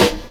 0203 DR.LOOP.wav